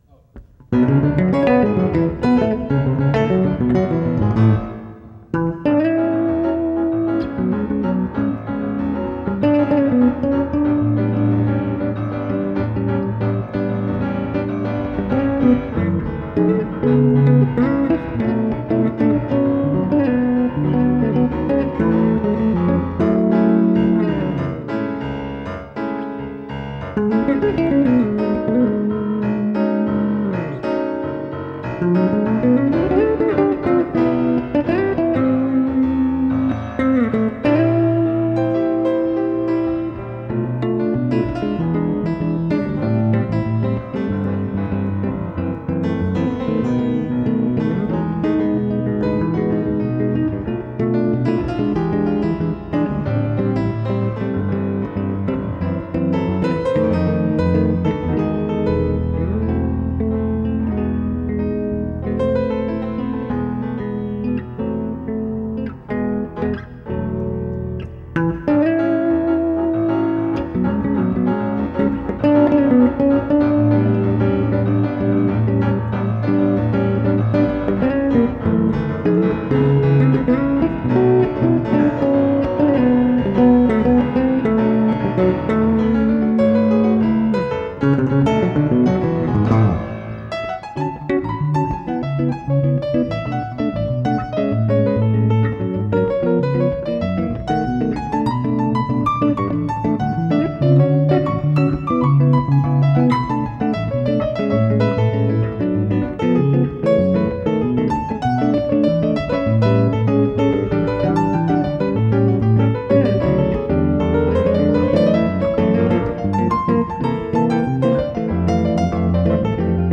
2008-07-25 오전 7:51:00 EBS SPACE 공감 방송에서 캡쳐하였습니다.